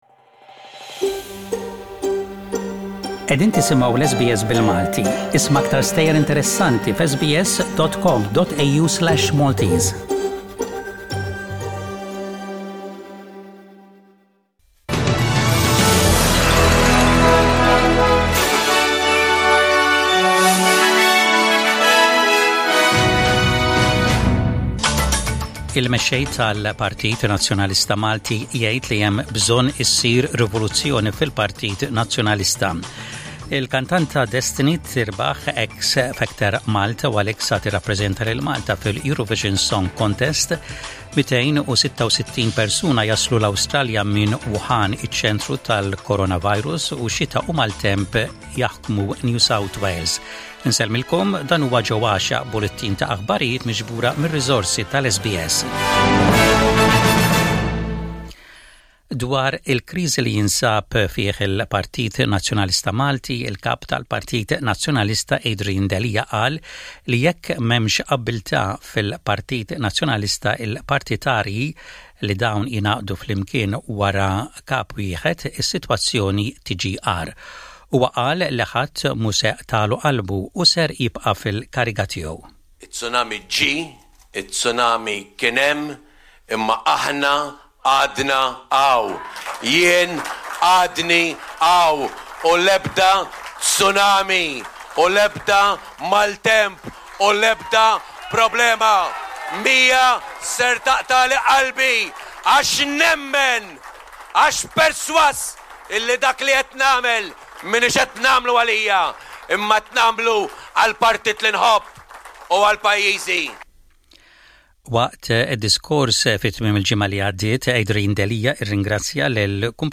SBS Radio | Maltese News: 11/02/20